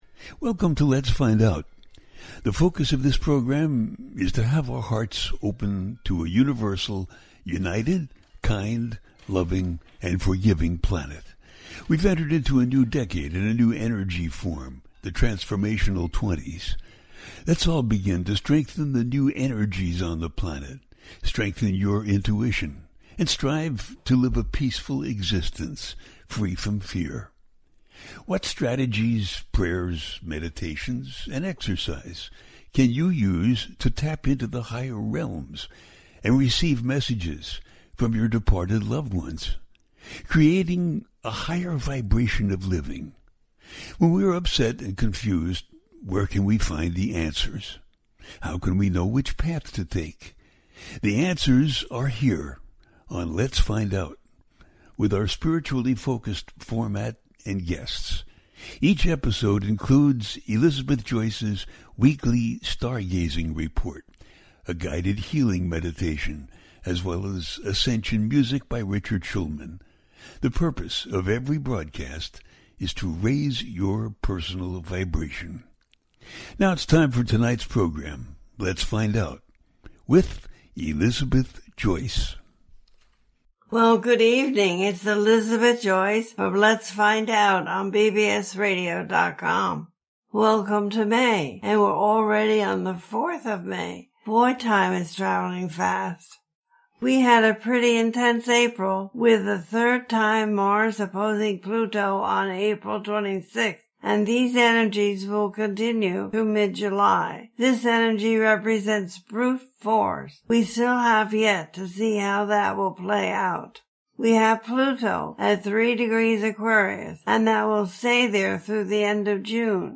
The Full Moon In Scorpio - Your May Astrology Sign by Sign- A teaching show
The listener can call in to ask a question on the air.
Each show ends with a guided meditation.